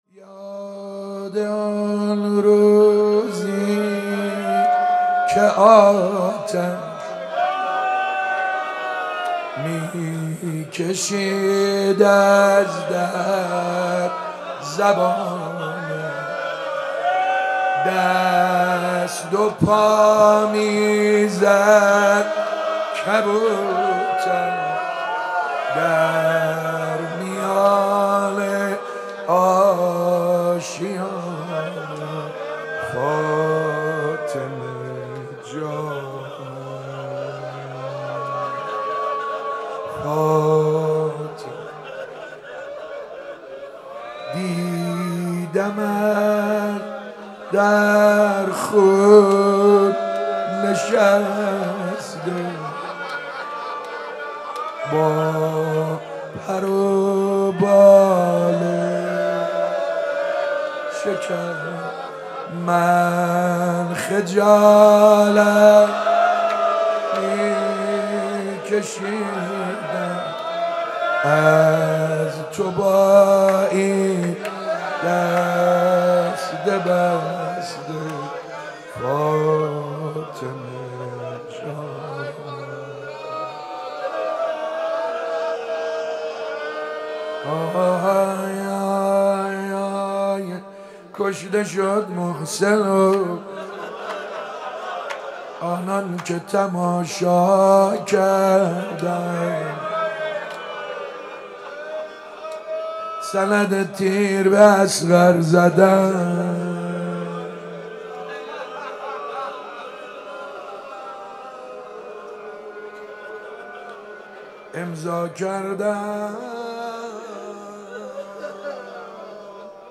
هفتگی 2 آذر 96 - روضه - یاد آن روزی که آتش